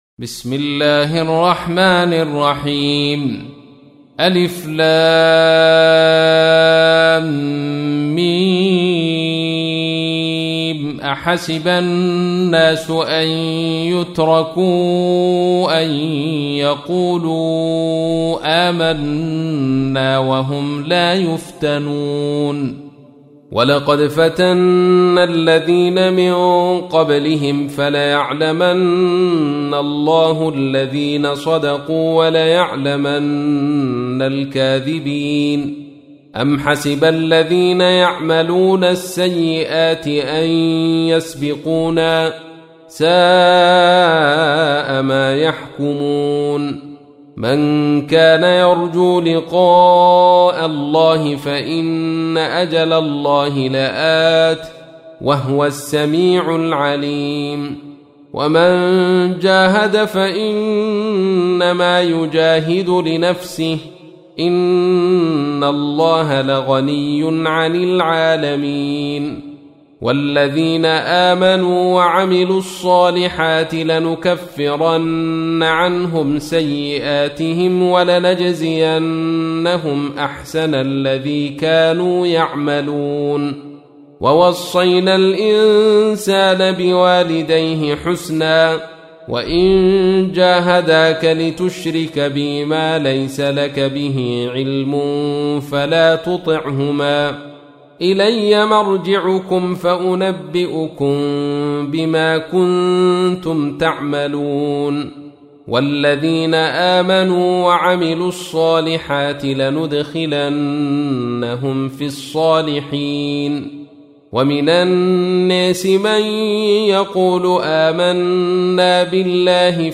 تحميل : 29. سورة العنكبوت / القارئ عبد الرشيد صوفي / القرآن الكريم / موقع يا حسين